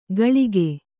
♪ gaḷige